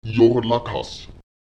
Die Betonung verlagert sich auf die vorletzte Silbe.